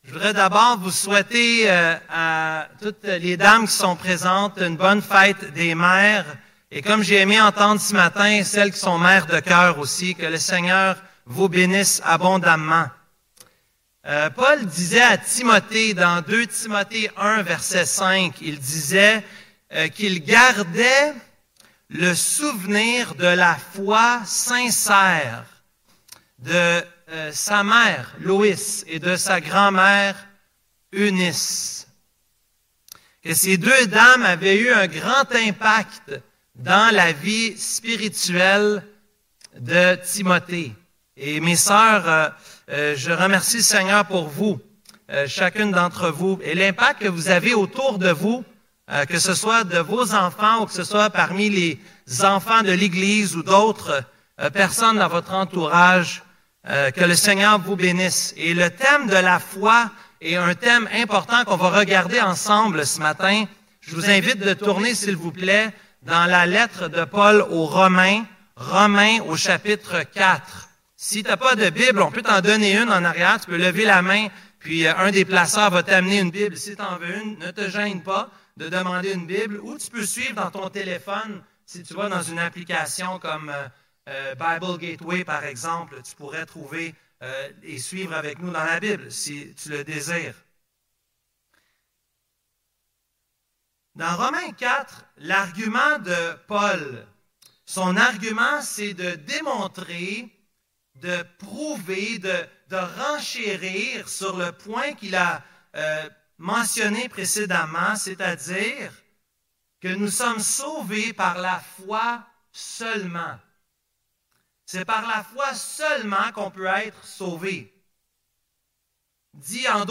Église Baptiste Évangélique Emmanuel - Culte du 12 Mai 2024 - Romains 4.9-25